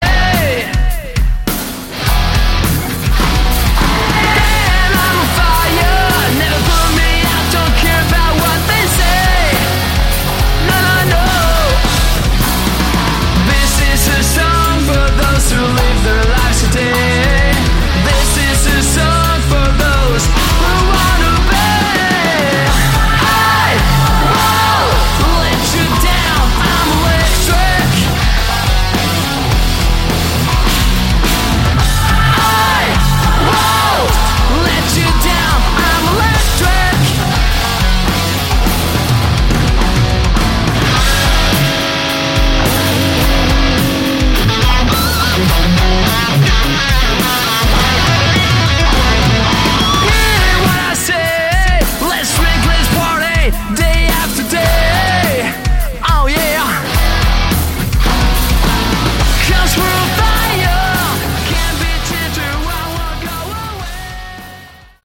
Category: Hard Rock
vocals
guitar
bass
drums